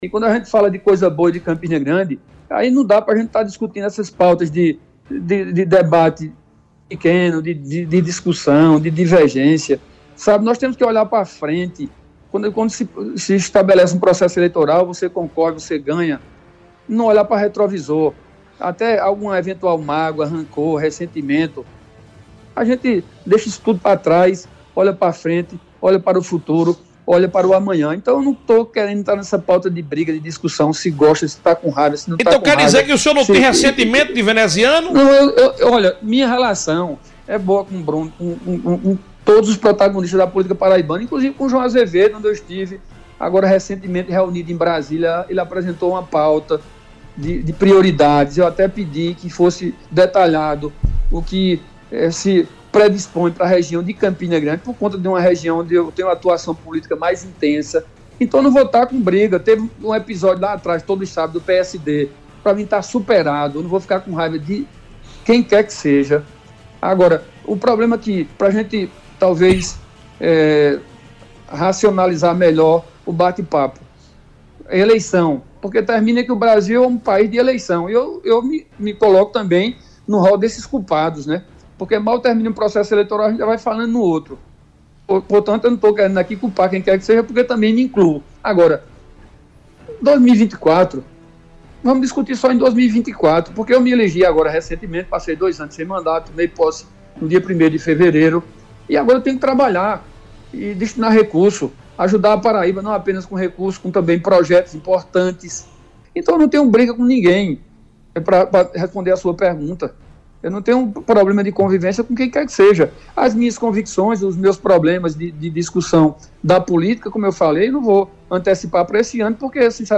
Durante entrevista concedida ao programa “60 Minutos” do Sistema Arapuan de Comunicação na noite desta terça-feira (26), o parlamentar enfatizou que não existe distanciamento ou ressentimentos em relação ao atual gestor da cidade, mas deixou em aberto a possibilidade de considerar uma candidatura no próximo ano.